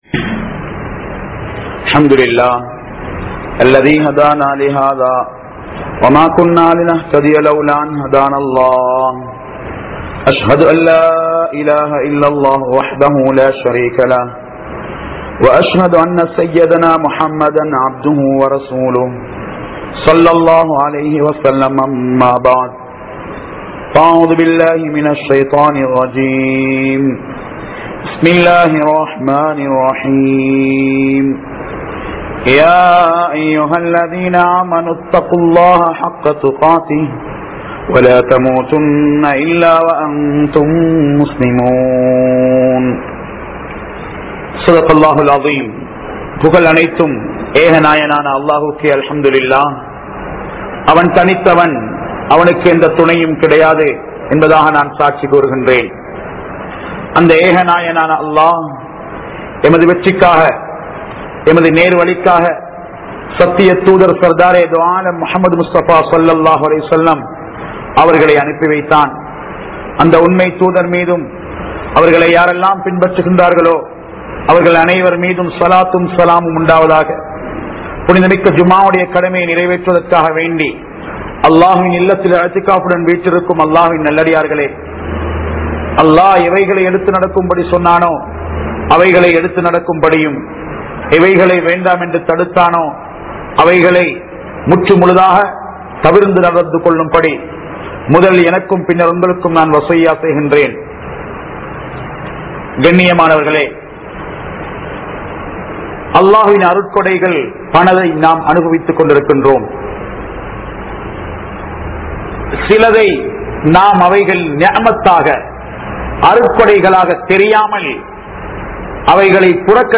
Meendum Kidaikkaatha Paakkiyam (மீண்டும் கிடைக்காத பாக்கியம்) | Audio Bayans | All Ceylon Muslim Youth Community | Addalaichenai
Kandy, Ilukkuwaththa Jumua Masjidh